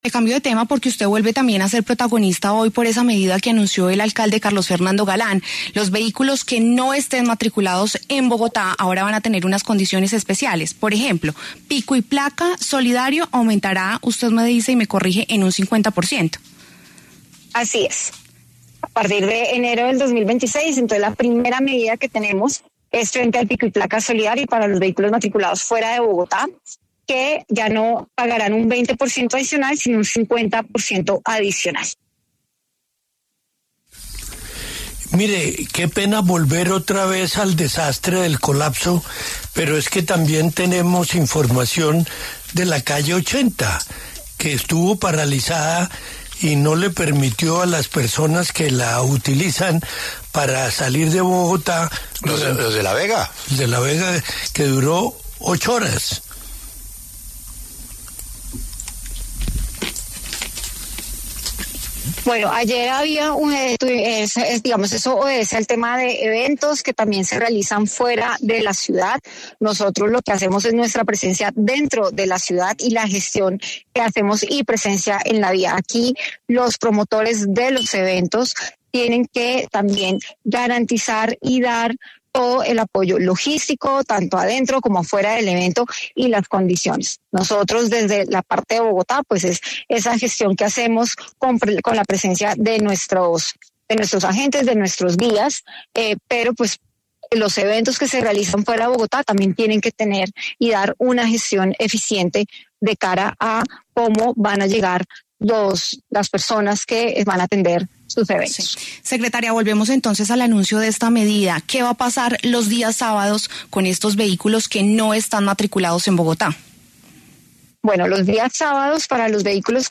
La secretaria de Movilidad, Claudia Díaz, explicó en los micrófonos de W Radio las dos nuevas medidas de movilidad dirigidas exclusivamente a los vehículos particulares matriculados fuera de Bogotá.